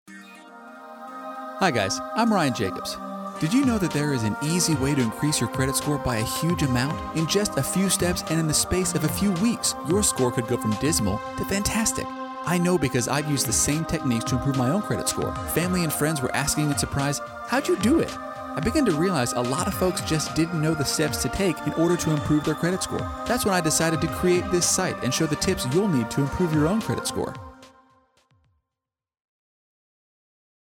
Male
English (North American)
Adult (30-50)
Studio Quality Sample
Explainer Videos